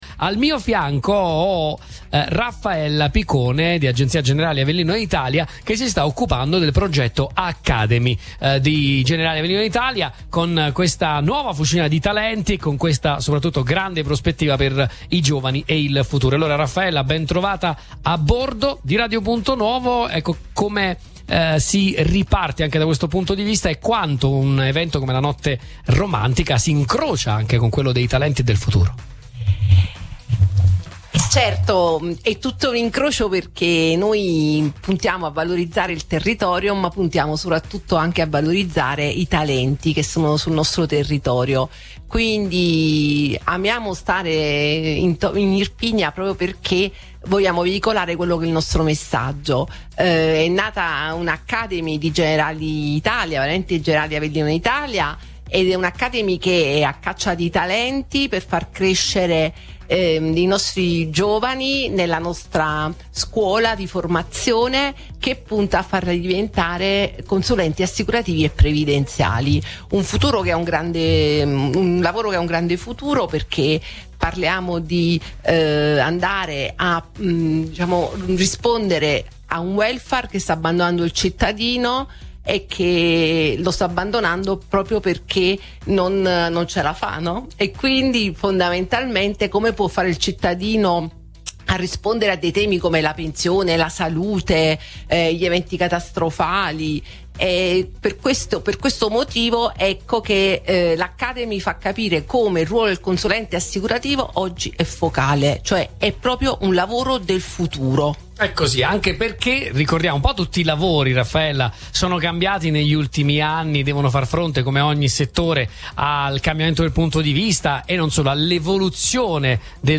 Sabato 21 giugno, in occasione del solstizio d’estate, il borgo di Summonte, tra i più belli d’Italia, ha ospitato una nuova edizione della Notte Romantica, evento patrocinato dal Comune di Summonte, in collaborazione con Generali Avellino Italia e Radio Punto Nuovo.